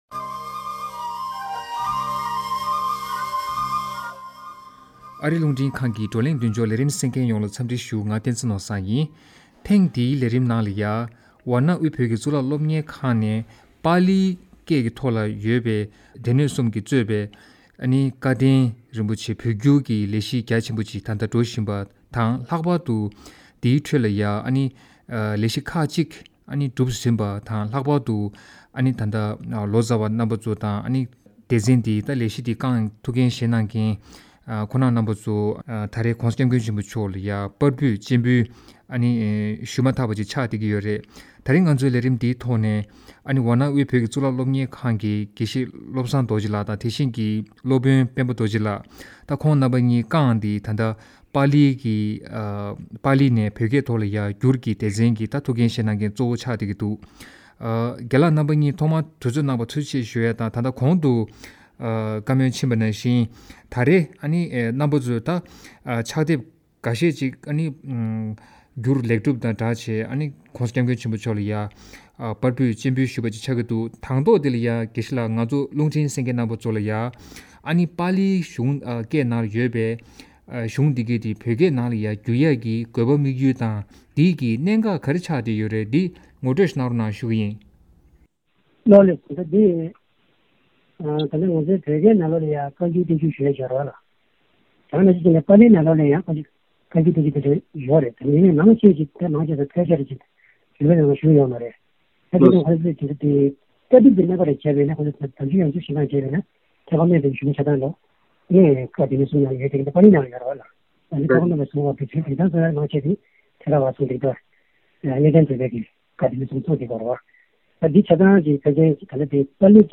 གླེང་མོལ་ཞུས་ཡོད།